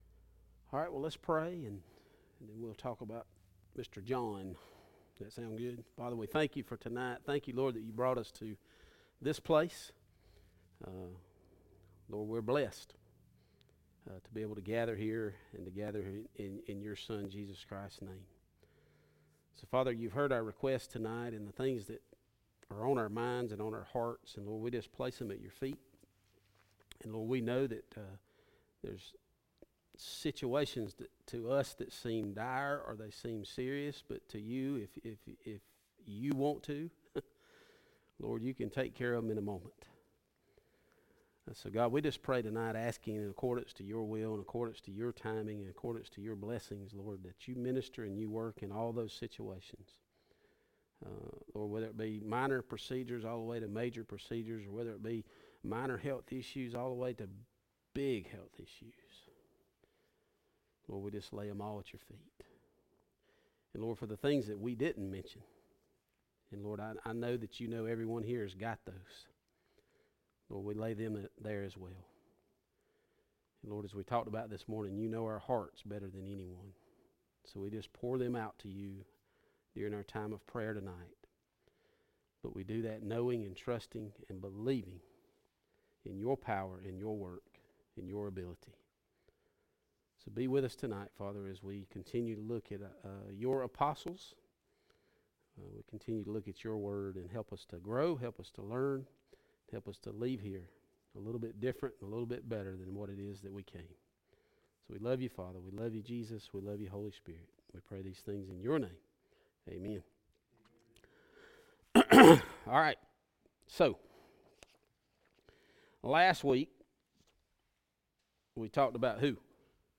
Message Type - Sermon
Occasion - Sunday Evening